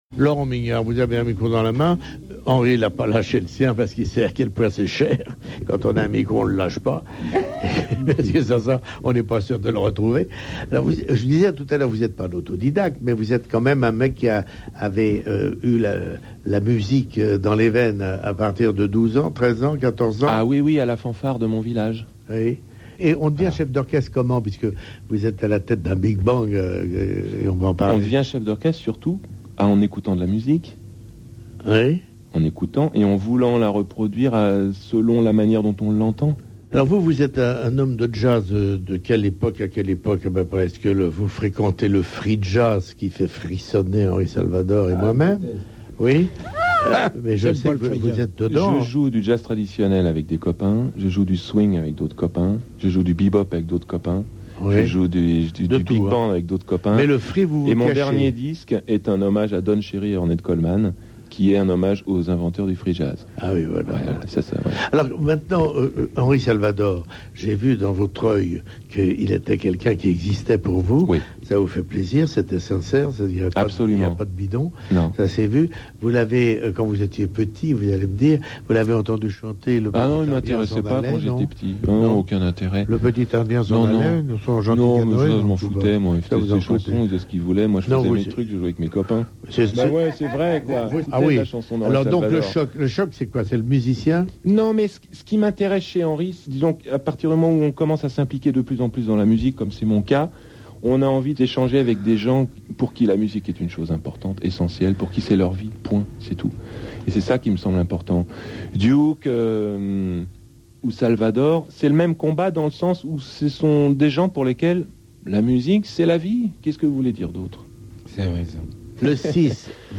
2003 LMDO itw France Inter PopClub Jose Arthur
2003_itw_FranceInter_PopClub_JoseArtur.MP3